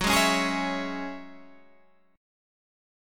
Gb7sus2 chord